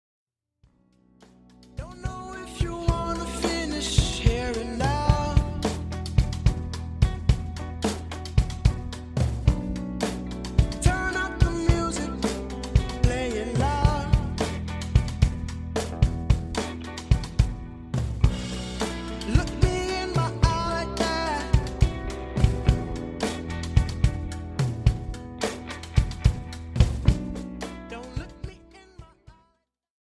Rnb
chill jam
featuring drum, bass, guitar, and vocals.